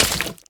Minecraft Version Minecraft Version snapshot Latest Release | Latest Snapshot snapshot / assets / minecraft / sounds / mob / bogged / hurt2.ogg Compare With Compare With Latest Release | Latest Snapshot
hurt2.ogg